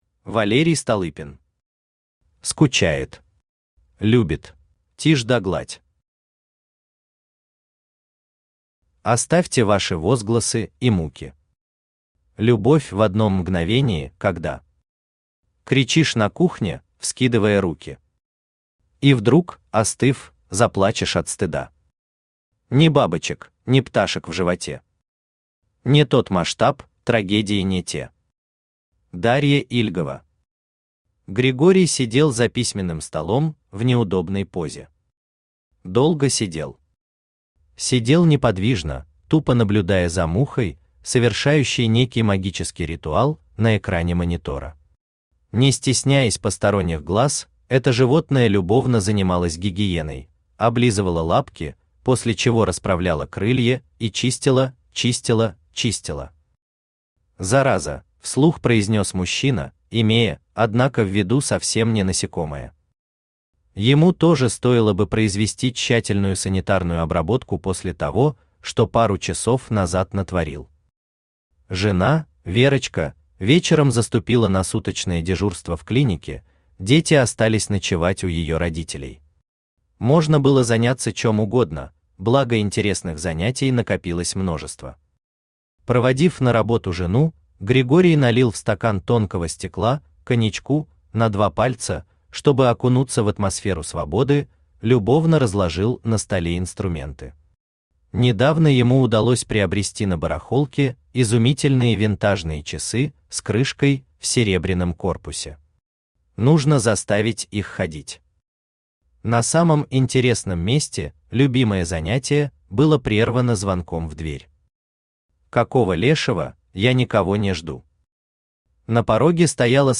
Аудиокнига В свой сон тебя не приглашу | Библиотека аудиокниг
Aудиокнига В свой сон тебя не приглашу Автор Валерий Столыпин Читает аудиокнигу Авточтец ЛитРес.